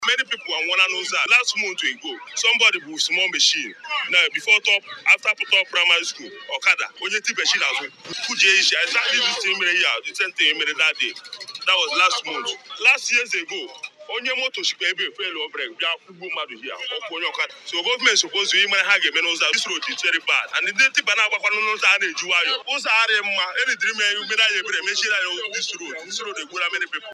Also speaking, another road user